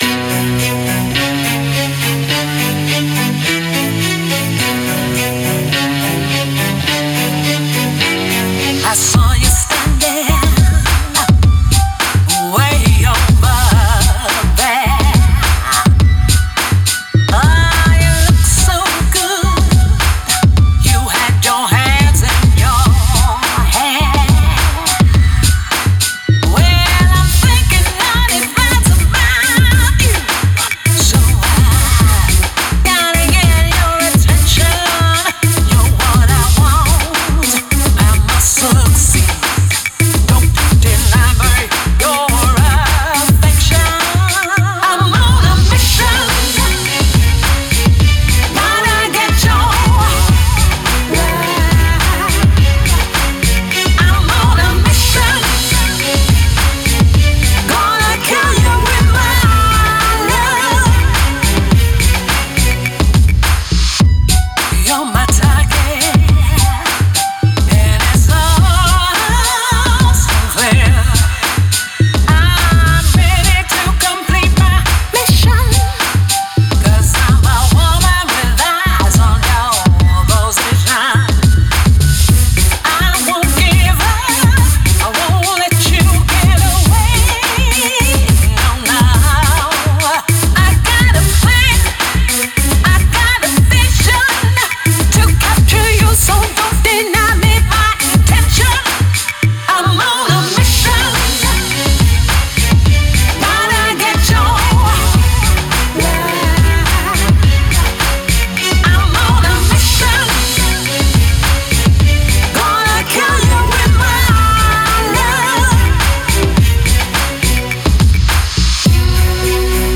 Un nuevo sencillo cinematográfico de pop y RnB.